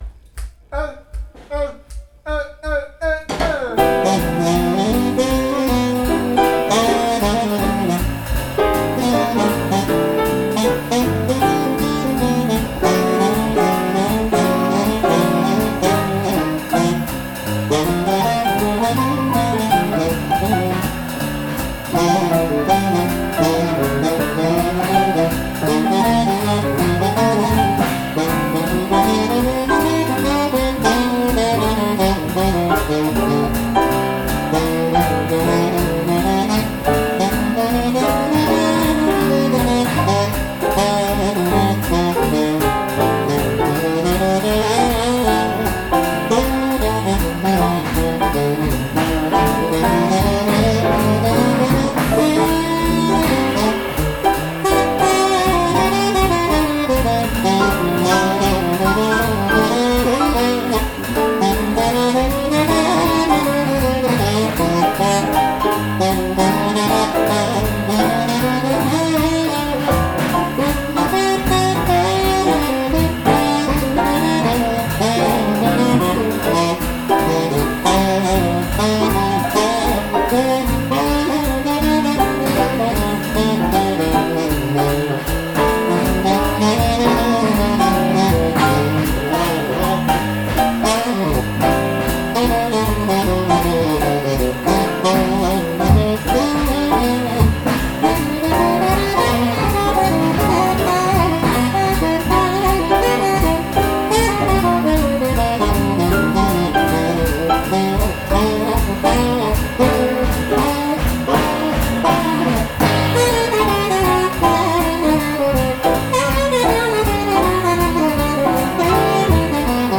Some on-going work with our jazz quartet:
"Live" at biweekly jam sessions:
A tune is called by someone and, whether we know it or not, we read down the melody and improvise over the chord changes.
The following recordings were made with my handheld digital Tascam DR-40 stereo recorder using its dual, built-in microphones.  As a result, the sound quality varies somewhat due to varying distances from the musicians.  In addition, since these were jams and not public performances, there are some missed notes and mistakes.
tenor sax
piano
electric bass
drums